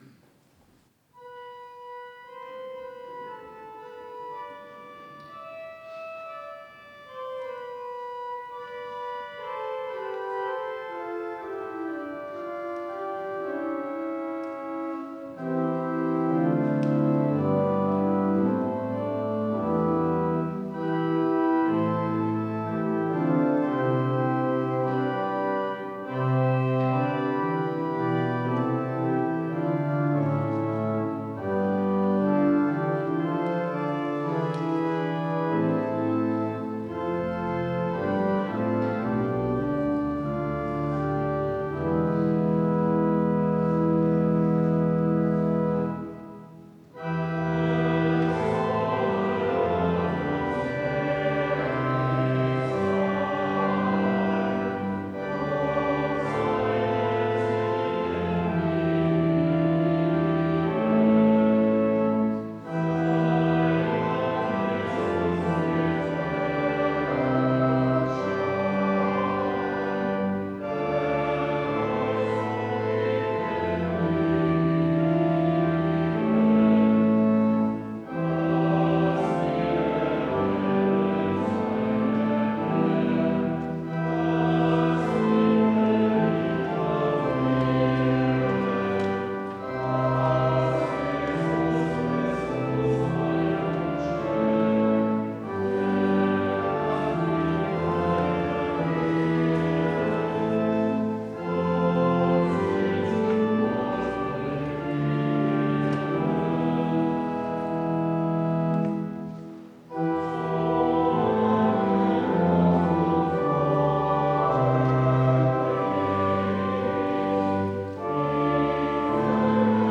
Gottesdienst am 23.02.2025
Es wolle Gott uns gnädig sein... (LG 275) Ev.-Luth. St. Johannesgemeinde Zwickau-Planitz